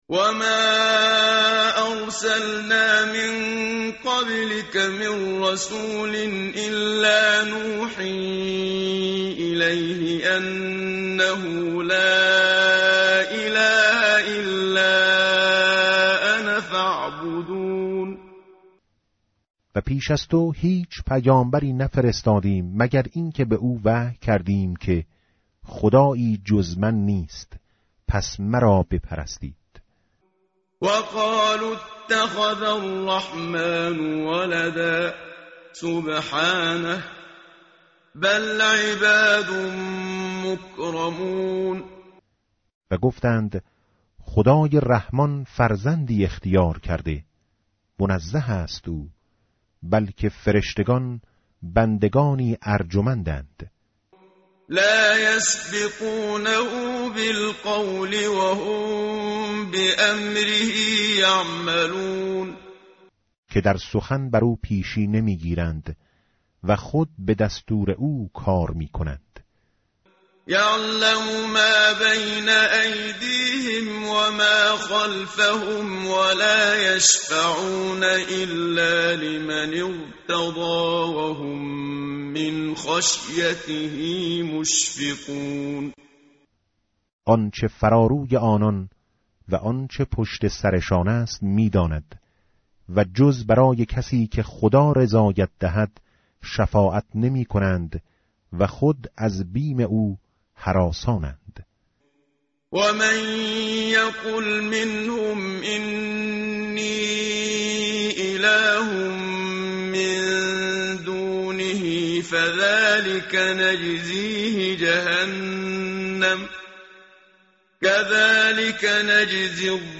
tartil_menshavi va tarjome_Page_324.mp3